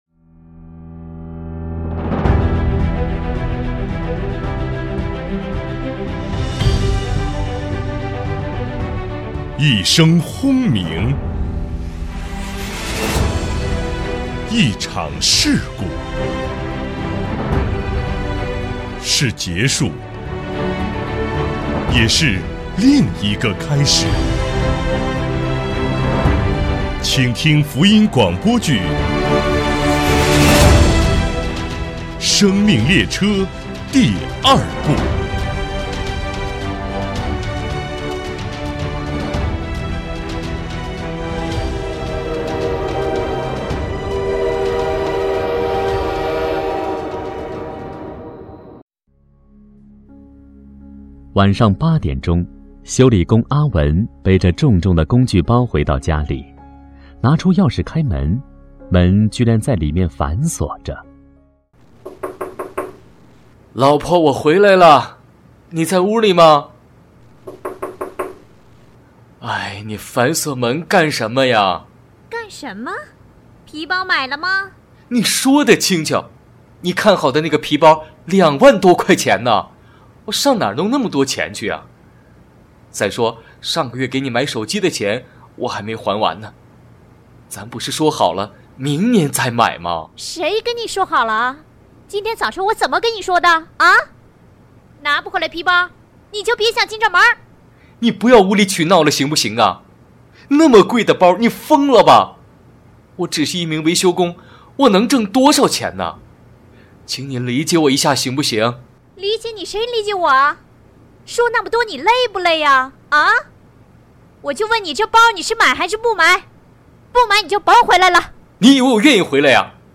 自录《广播剧》：生命列车